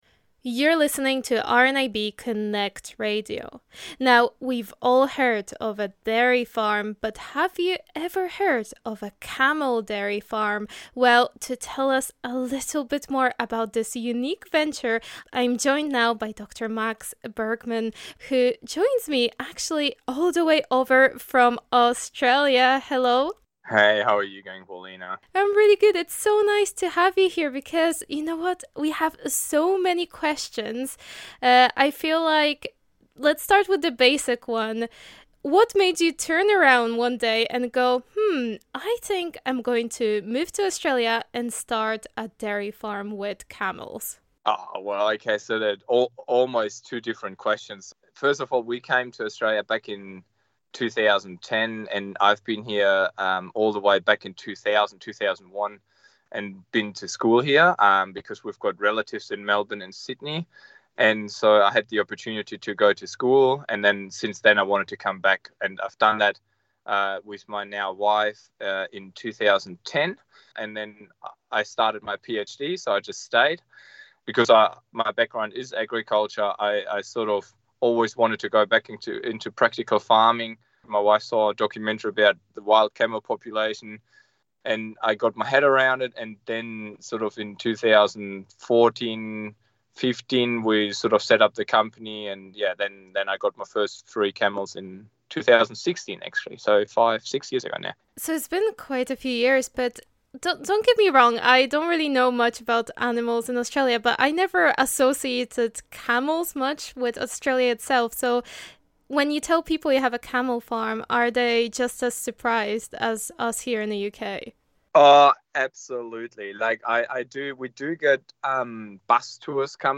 In Conversation With VI Camel Farmer